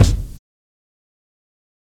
Kicks
BD - Proud Preemo.wav